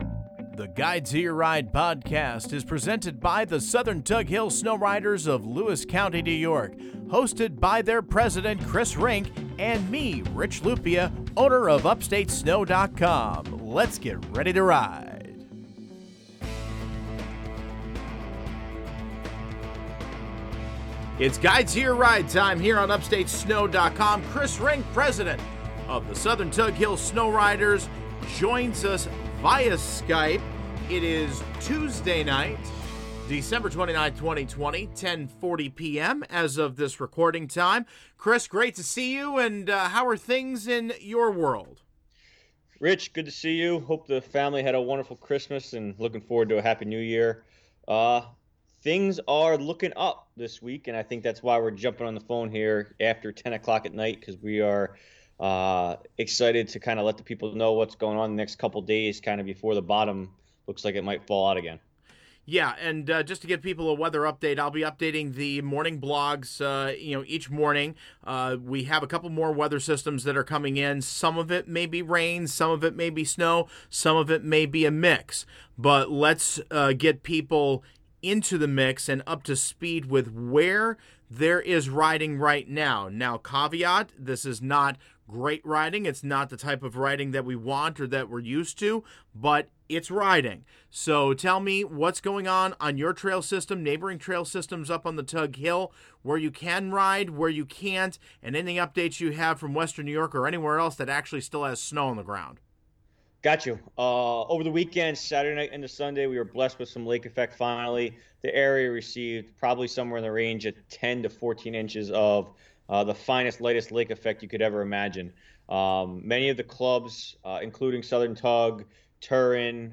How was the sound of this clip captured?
Recorded via Skype 12.29.2020